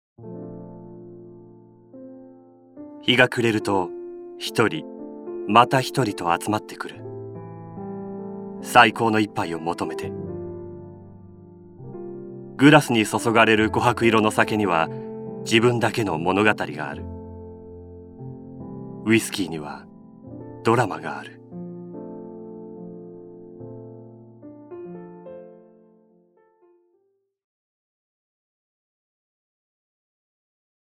ボイスサンプル
ウィスキーCM